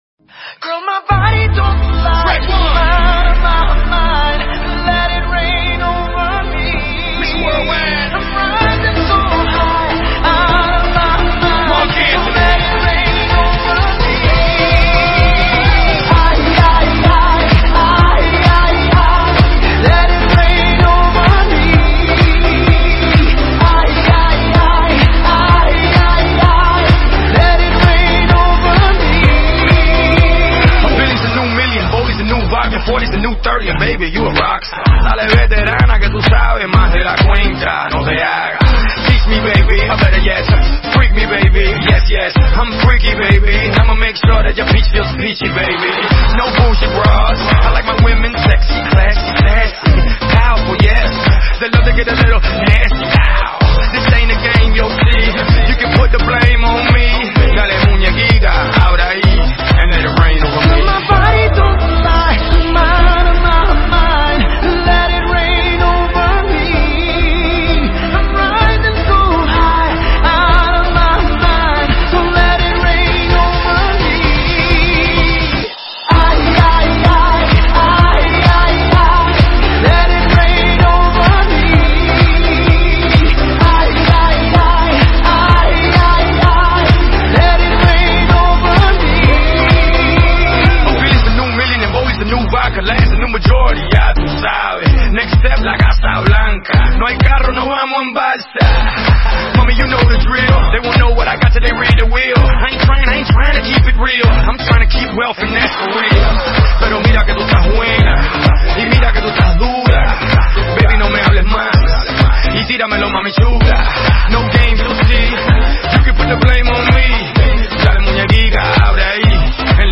Nhạc Mỹ Latinh